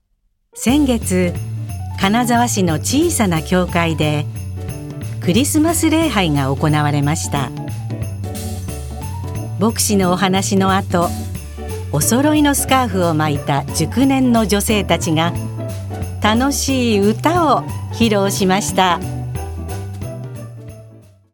ボイスサンプル
ナレーション